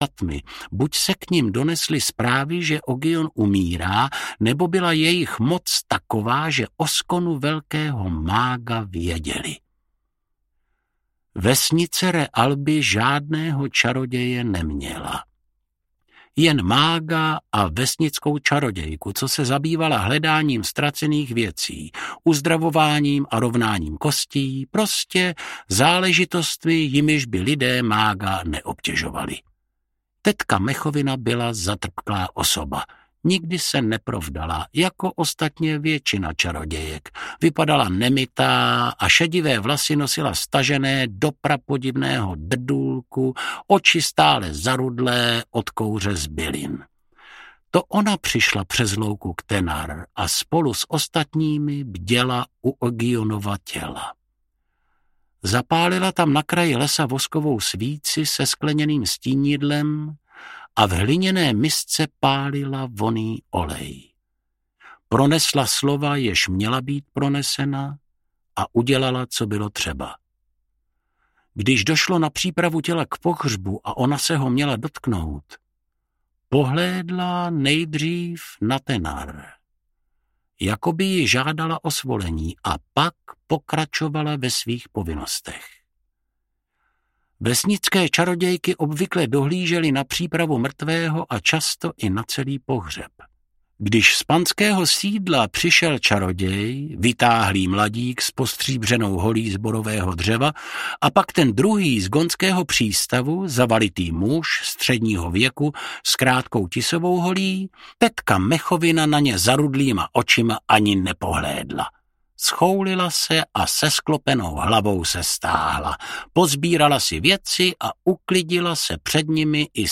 Tehanu audiokniha
Ukázka z knihy
Vyrobilo studio Soundguru.